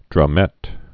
(drŭm-ĕt)